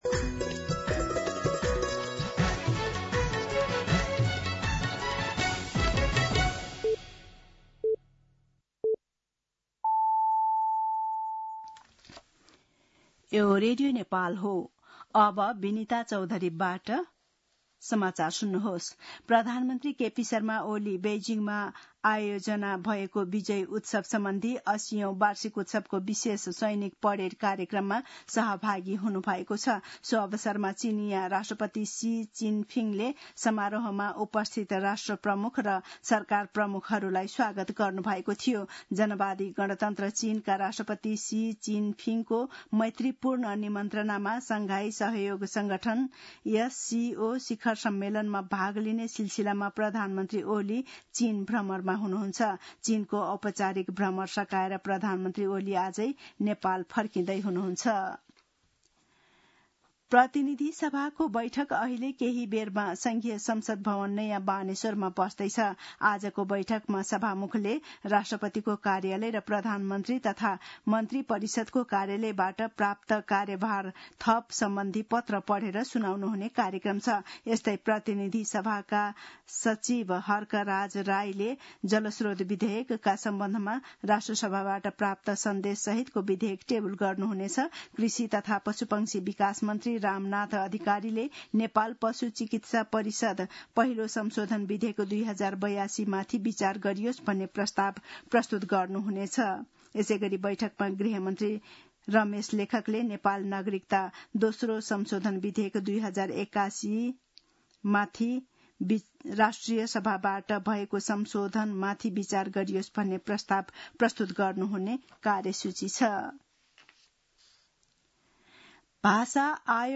दिउँसो १ बजेको नेपाली समाचार : १८ भदौ , २०८२
1-pm-News.mp3